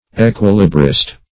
Search Result for " equilibrist" : The Collaborative International Dictionary of English v.0.48: Equilibrist \E*quil"i*brist\, n. One who balances himself in unnatural positions and hazardous movements; a balancer.